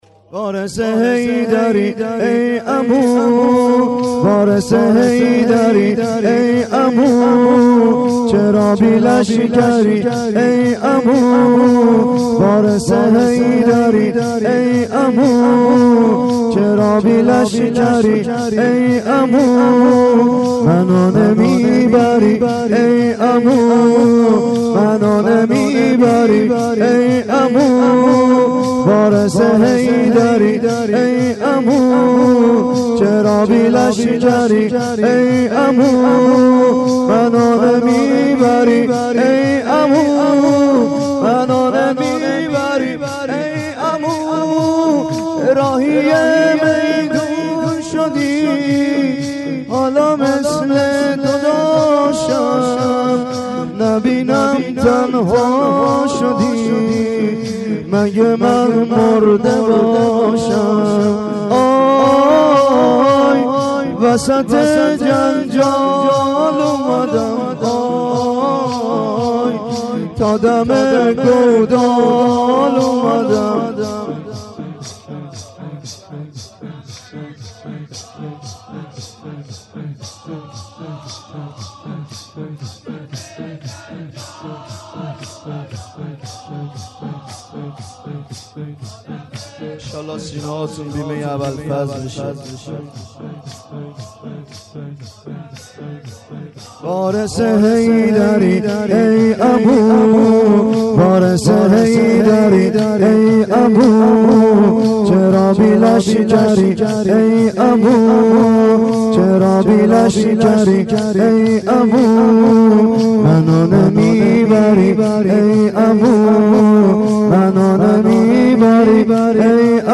گلچین زمینه های محرم 93
زمینه شب پنجم : وارث حیدری ای عمو ، چرا بی لشکری ای عمو